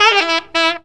COOL SAX 6.wav